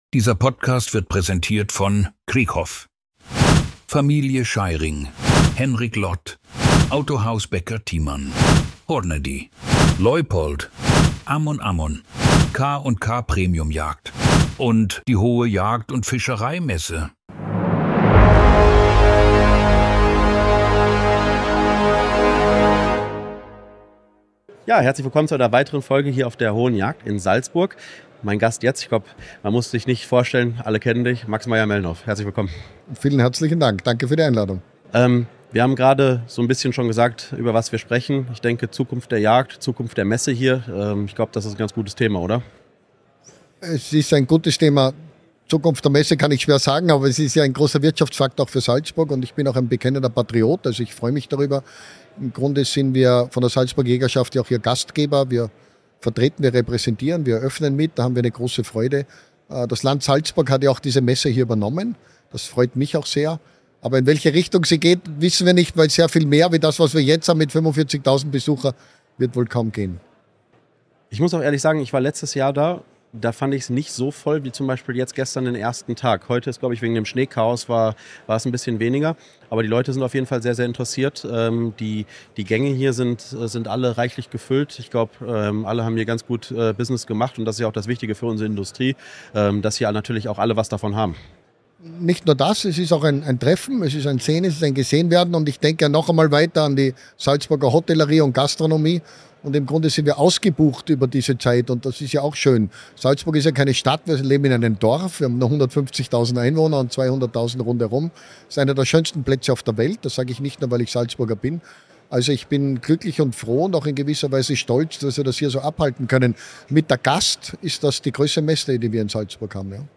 auf der Hohen Jagd in Salzburg